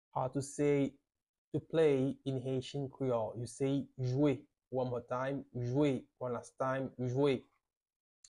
How to say "To Play" in Haitian Creole - "Jwe" pronunciation by a native Haitian Teacher
“Jwe” Pronunciation in Haitian Creole by a native Haitian can be heard in the audio here or in the video below: